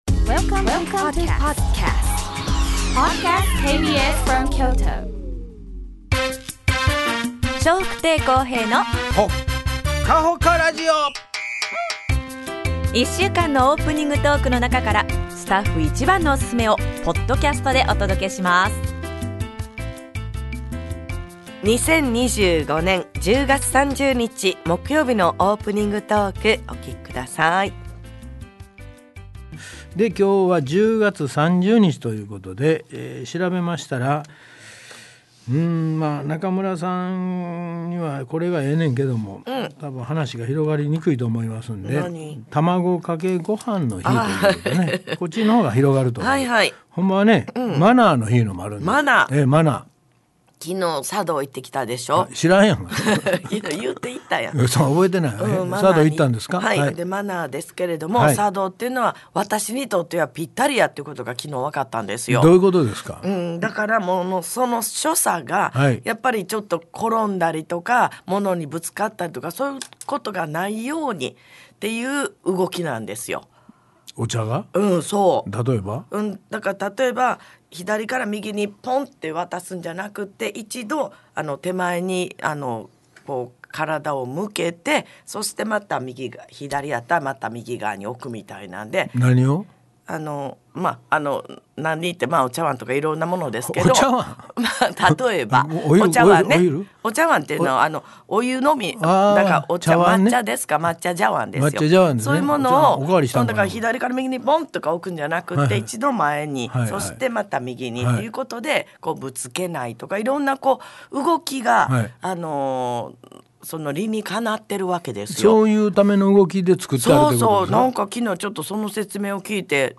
2025年10月30日のオープニングトーク